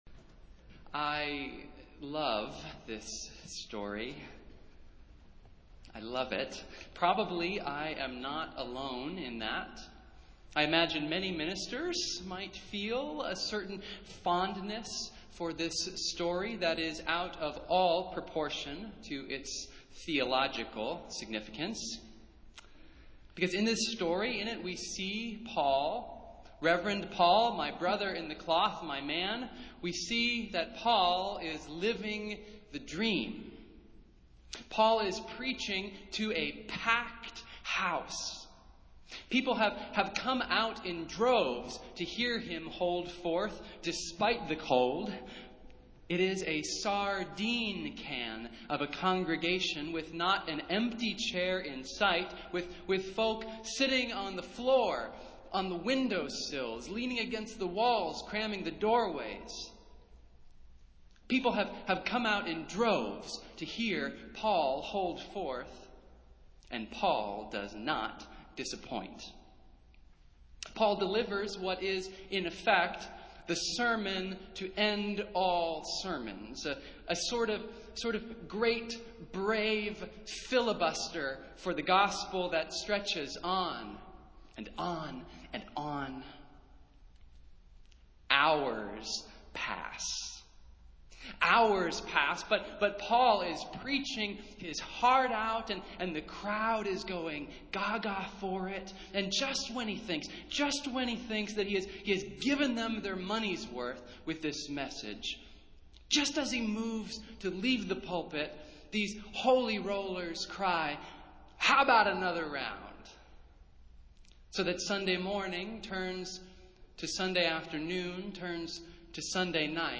Festival Worship - First Sunday in Lent